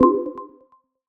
etfx_explosion_scan.wav